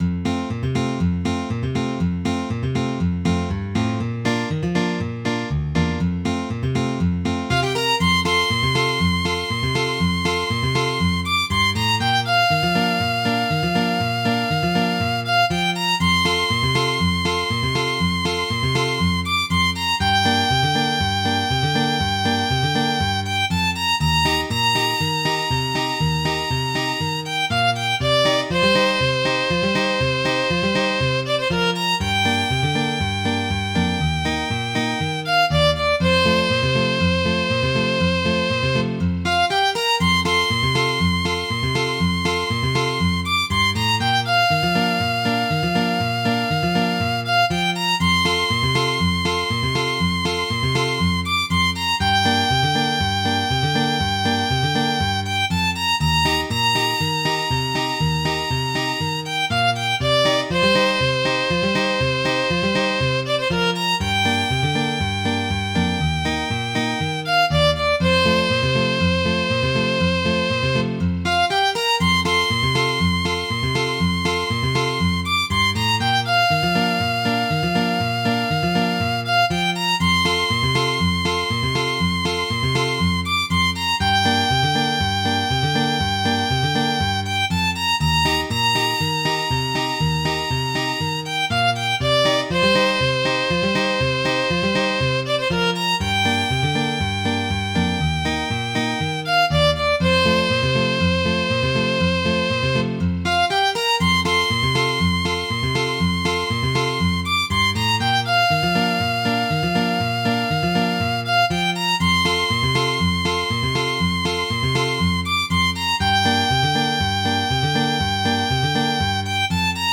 Midi File, Lyrics and Information to Silver Dagger